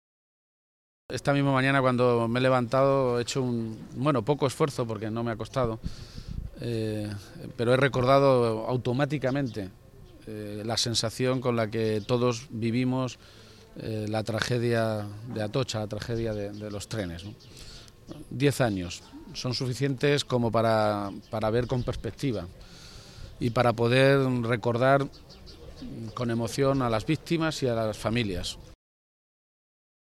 García-Page hacía esas declaraciones a preguntas de los medios de comunicación, esta mañana, en Toledo, e insistía en que “esta experiencia extraordinariamente trágica que le tocó vivir a España ha puesta de manifiesto un comportamiento absolutamente sabio y responsable de la sociedad y, sobre todo, de las familias de las víctimas de aquel atentado”.
Cortes de audio de la rueda de prensa